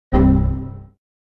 windowsXP_error.ogg